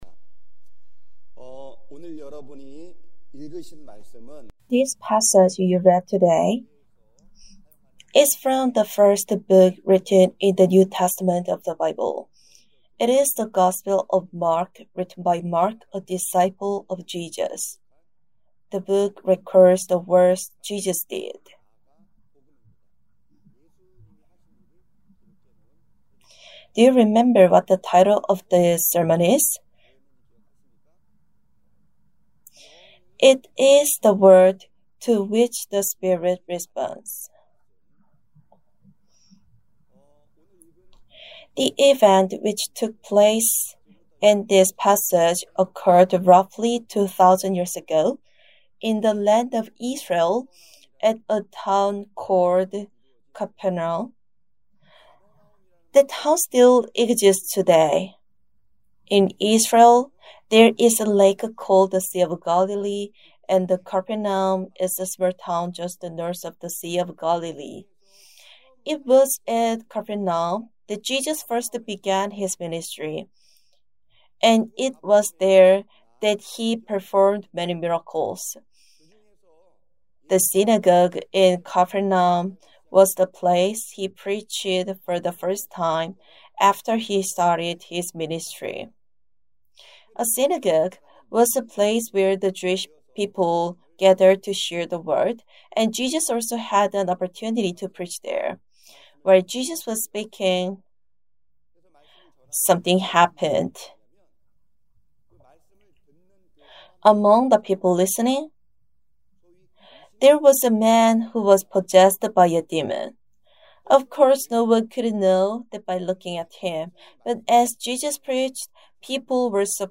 Do you remember what the title of this sermon is?